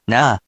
We’re going to show you the character, then you you can click the play button to hear QUIZBO™ sound it out for you.
In romaji, 「な」 is transliterated as「na」which sounds like 「nahh」which sounds like the kno in 「knot